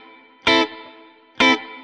DD_StratChop_130-Amin.wav